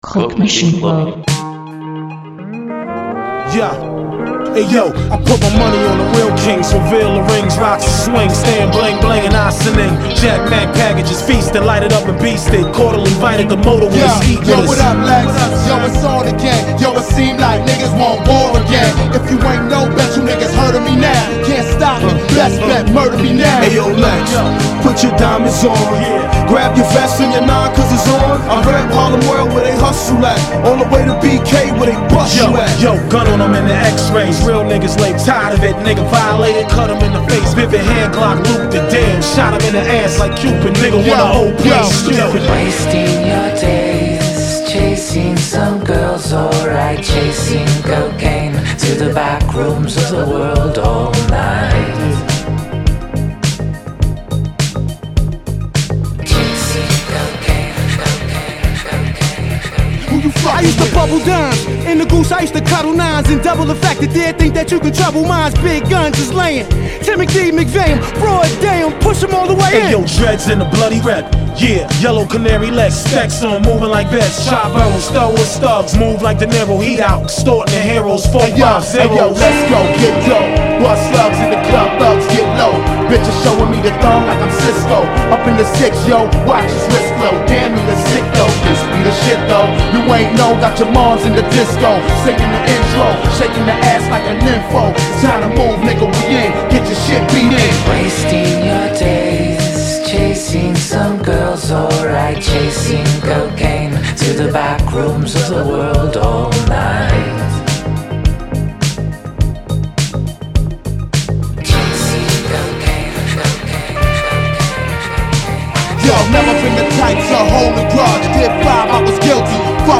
a mix